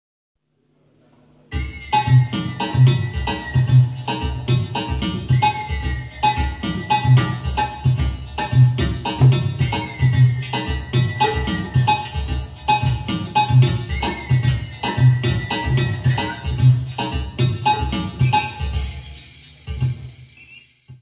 Área:  Jazz / Blues